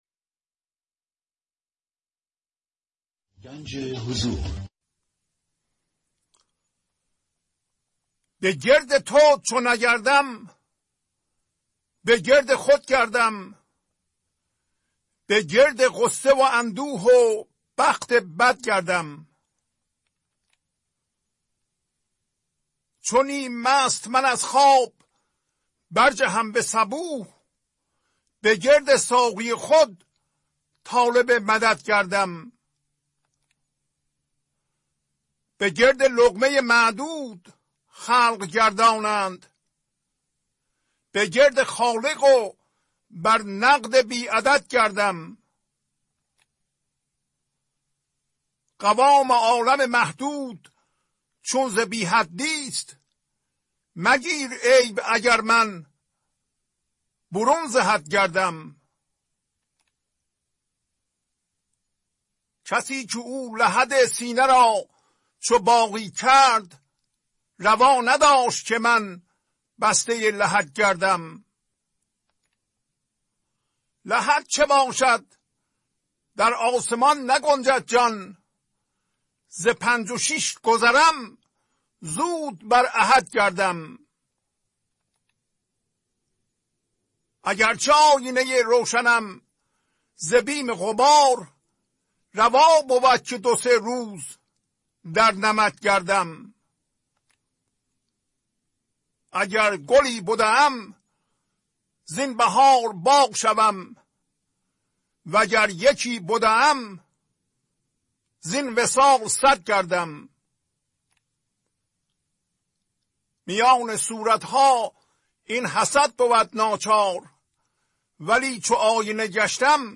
خوانش تمام ابیات این برنامه - فایل صوتی
965-Poems-Voice.mp3